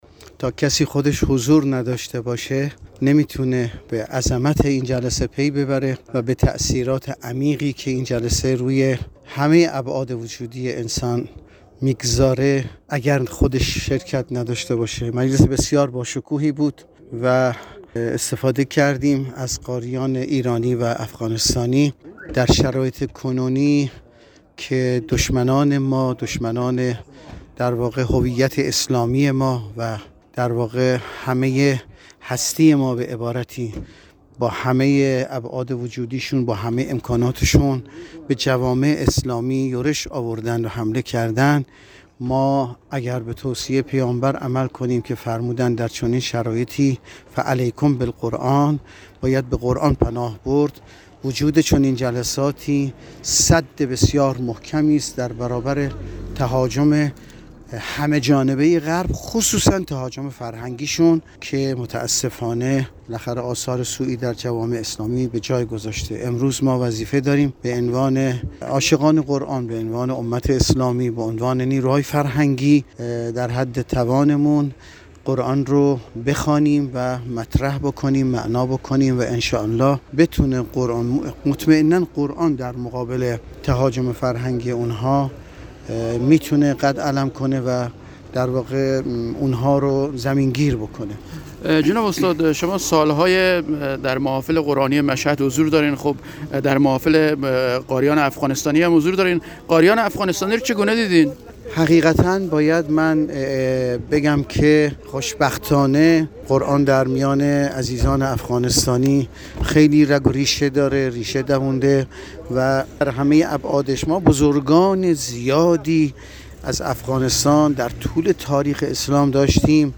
خبر / مصاحبه